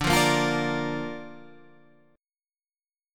Dm#5 chord